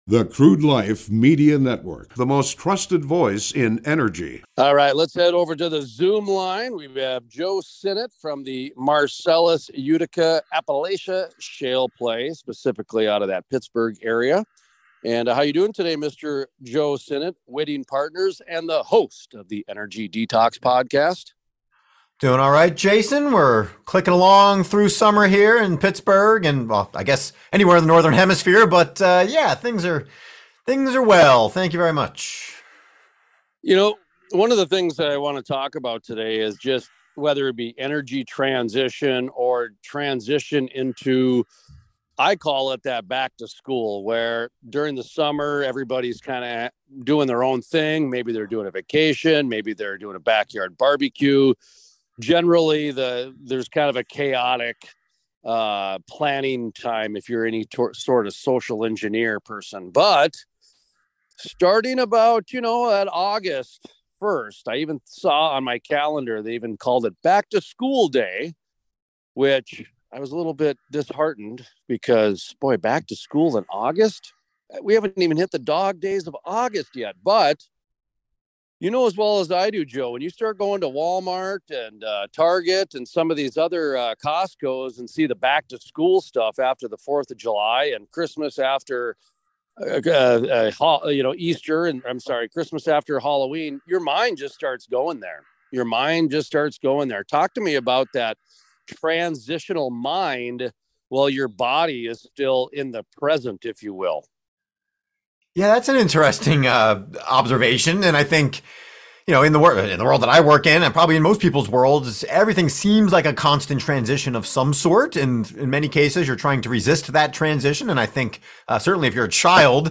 Plus the importance of slowing down and not overreacting to the changes is emphasized, as most day-to-day activities remain the same. The interview also highlights the significance of vacations in clearing the mind and refocusing. The two discuss the importance of purging unnecessary emails and reducing clutter to maintain clarity of thought.